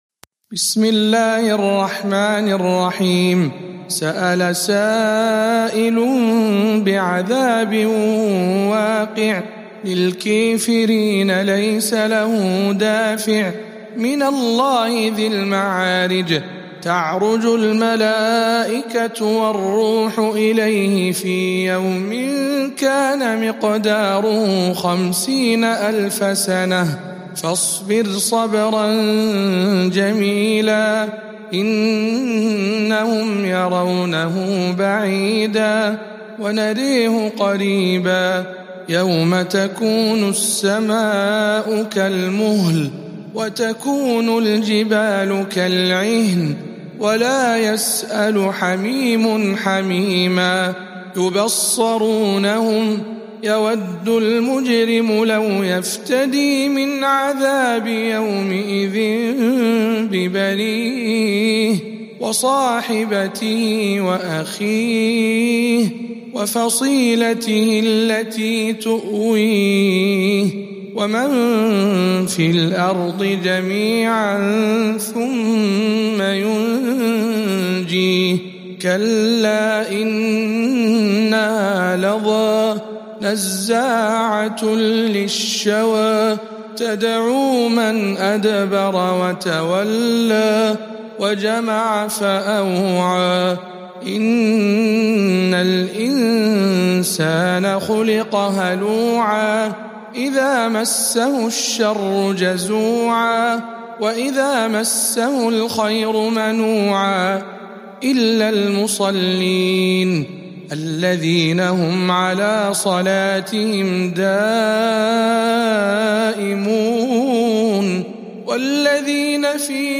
سورة المعارج برواية الدوري عن أبي عمرو